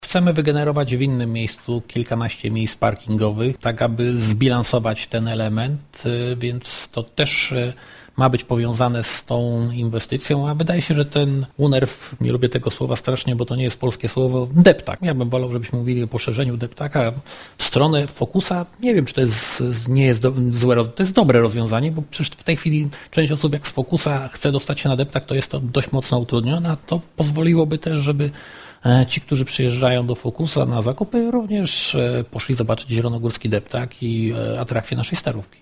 Rolę woonerfu miałaby przejąć np. część ulicy Kupieckiej, która kończy się na skrzyżowaniu z Wrocławską, przedłużając tym samym niejako deptak. O pomyśle mówił w audycji Prezydent na 96 FM Janusz Kubicki.